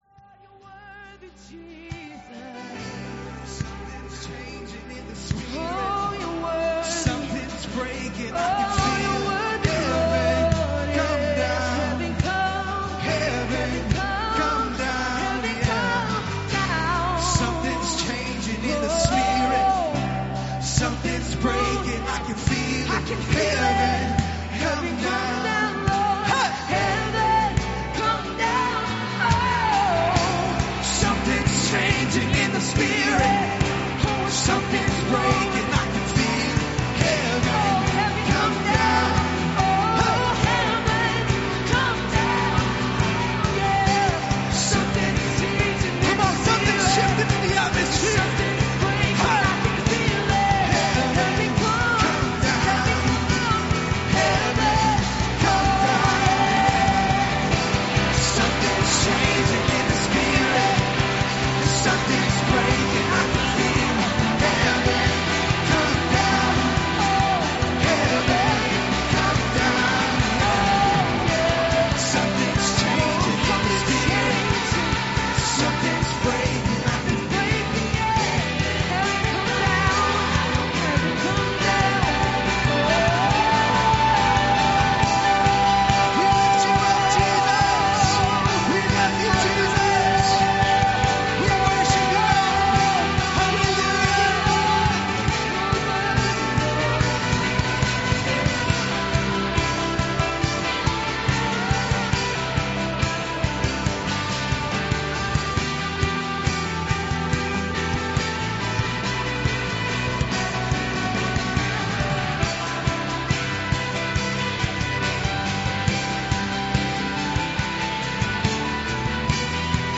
A message from the series "Calvary Gospel Church."